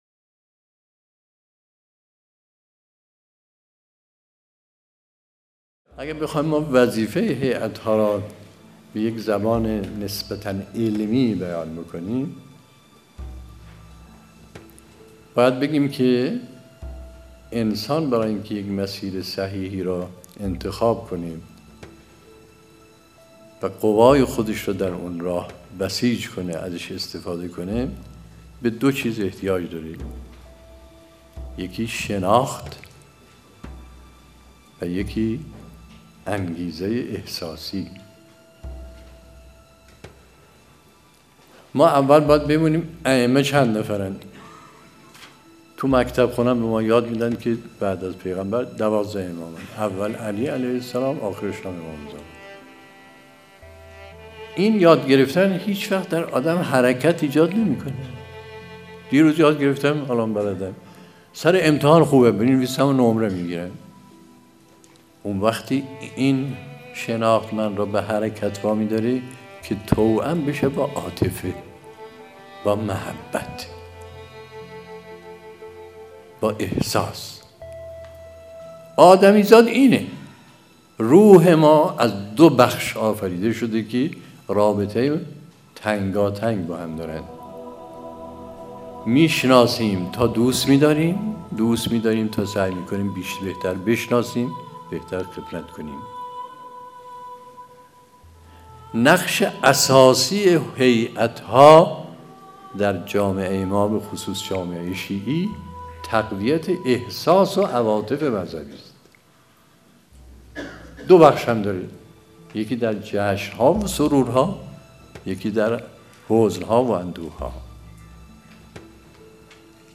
گزیده ای از سخنرانی آیت الله مصباح یزدی | دومین همایش ملی هیأت‌های محوری و برگزیده کشور | اسفندماه 1392-شهر مقدس قم | جامعه ایمانی مشعر